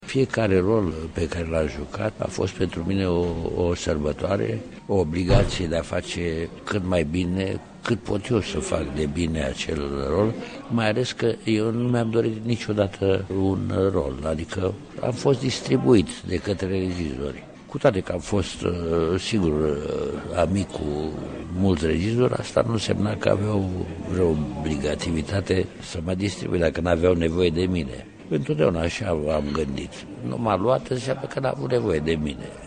Mitică Popescu mărturisea – într-un interviul difuzat la Radio România Cultural în 2018 – că niciodată nu a refuzat vreo partitură.